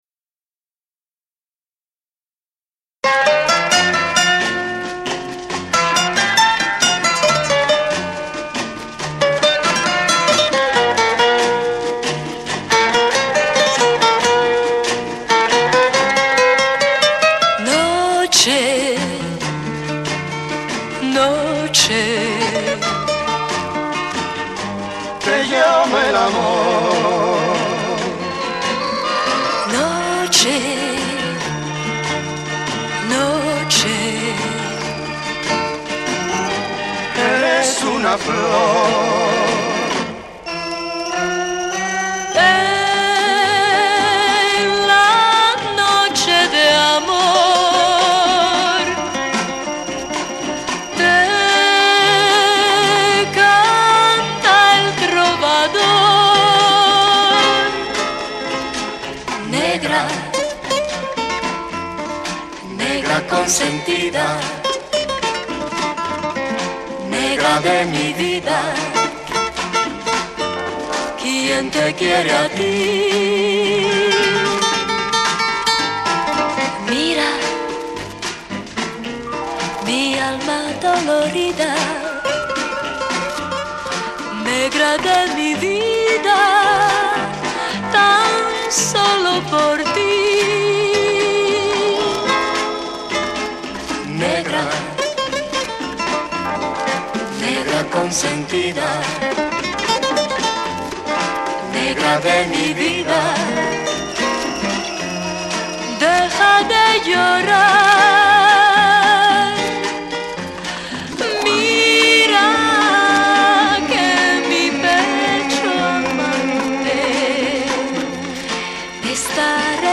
the wonderful voices and guitars of the famous trio